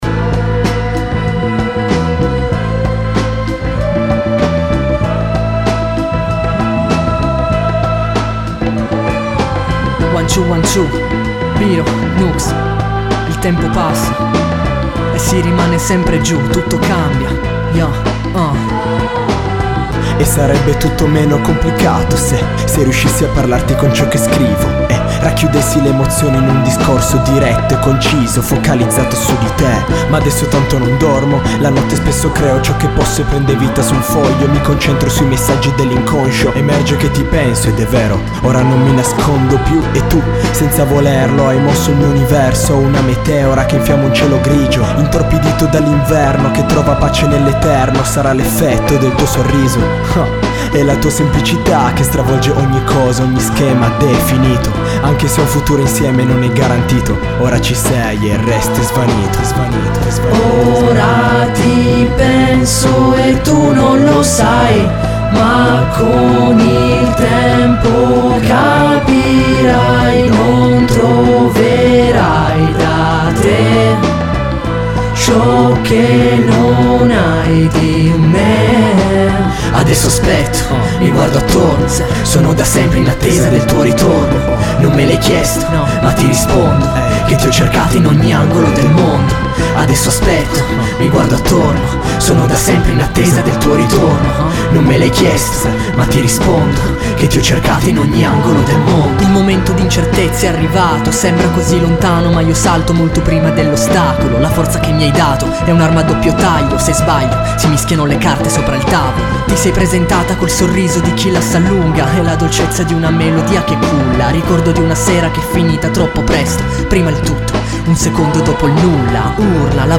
Genere: Hip-Hop/Funk/Soul